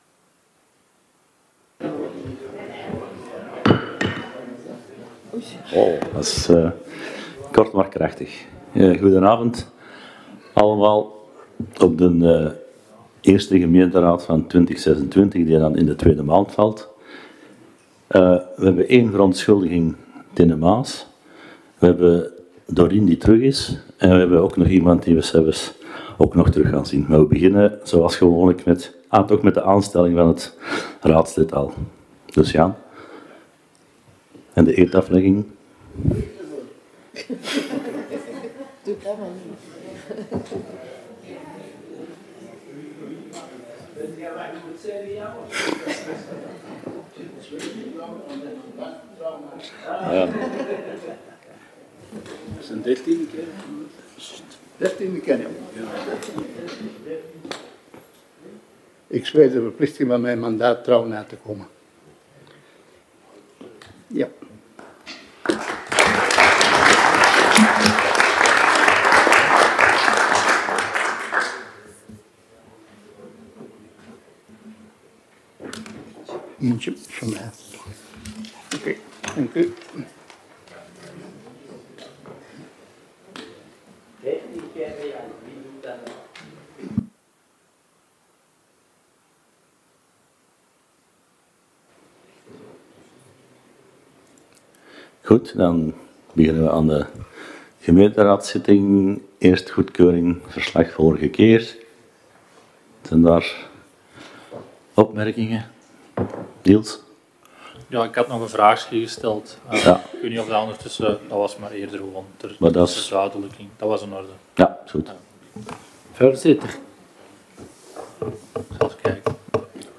Gemeentehuis